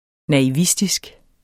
Udtale [ naiˈvisdisg ]